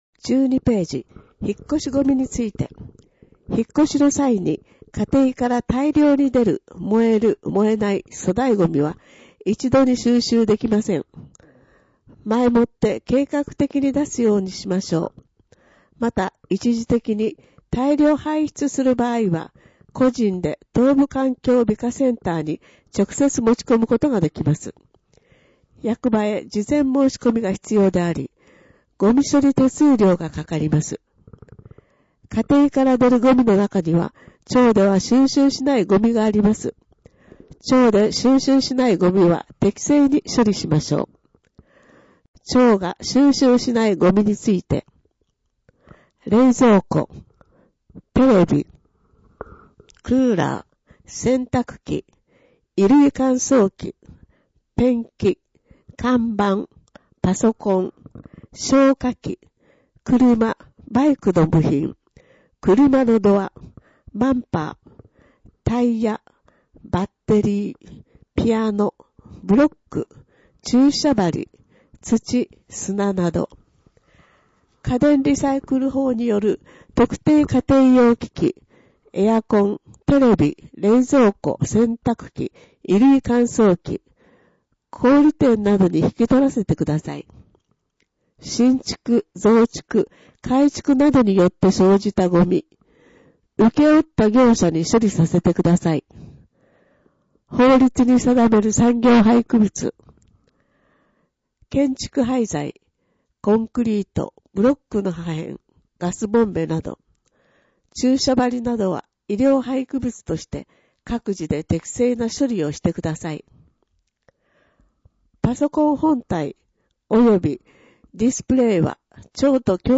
声の広報にしはらは、広報にしはらの情報を音声でお届けしています。
音訳ボランティアサークル「声の広報かけはし」が録音しています。